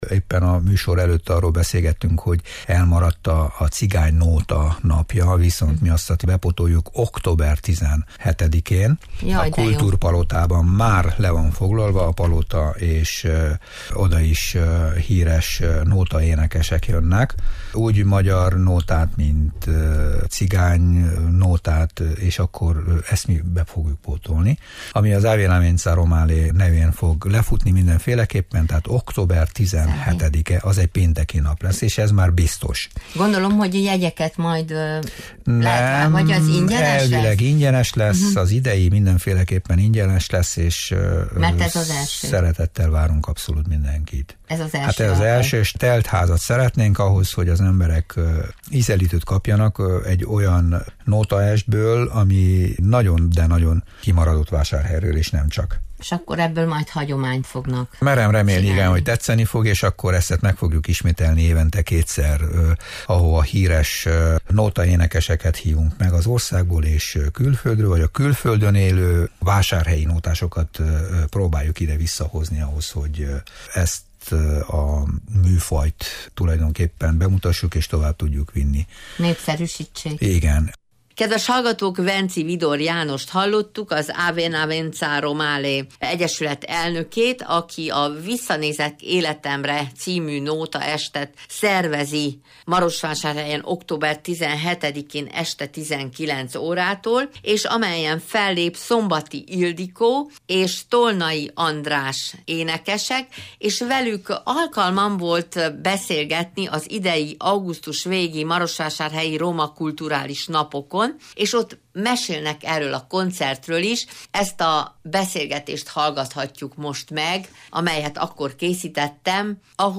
Erről a nótaestről beszélgettünk az idei Roma Kultúrális Napokon